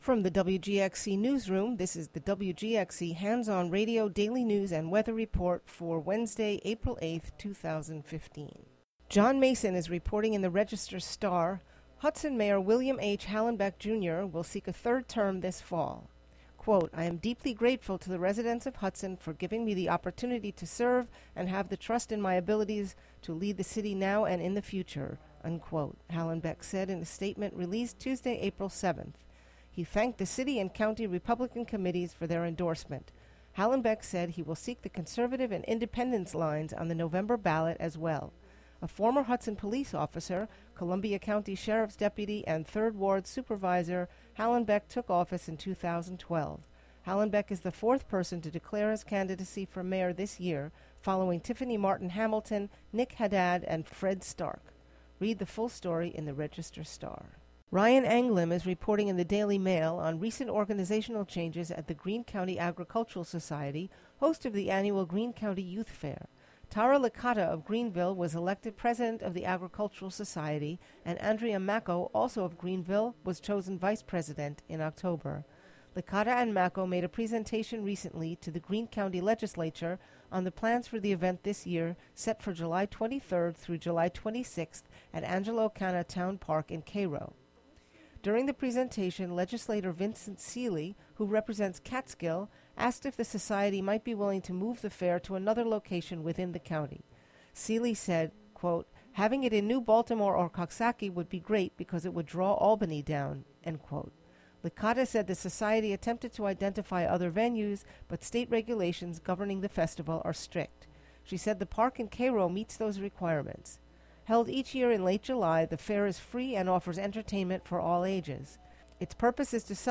Local news and weather for Wednesday, April 8, 2015.